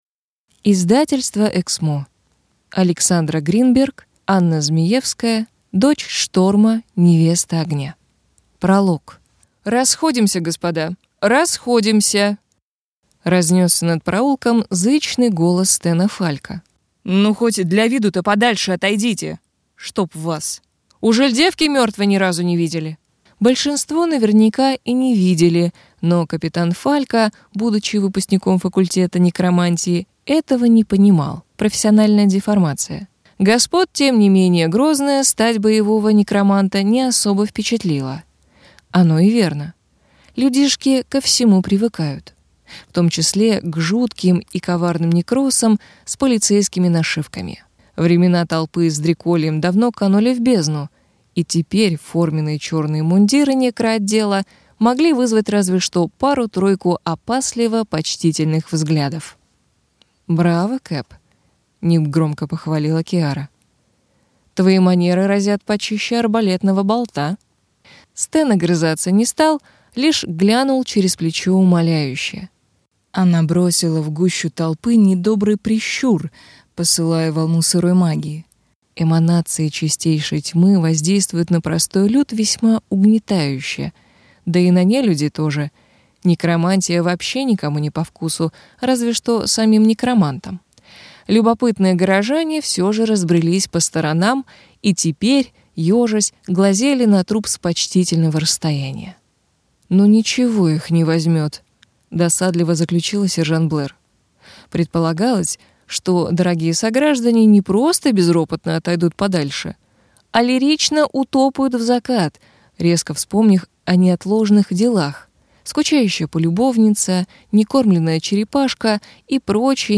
Аудиокнига Дочь шторма, невеста огня | Библиотека аудиокниг